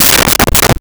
Knocking On Door With Open Hand
Knocking on Door with Open Hand.wav